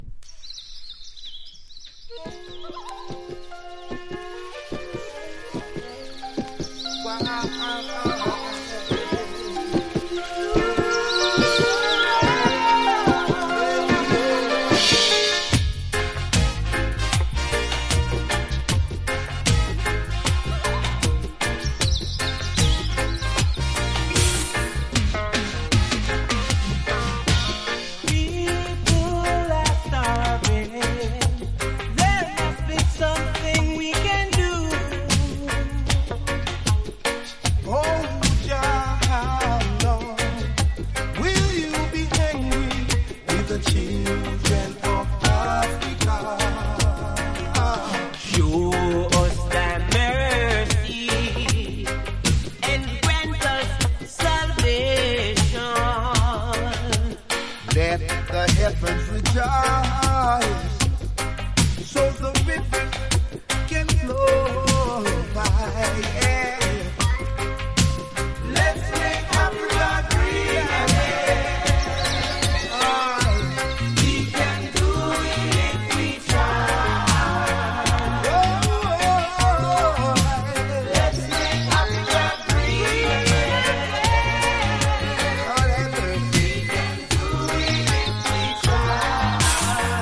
小鳥のさえずりからはじまるイントロも最高です。
ROOTS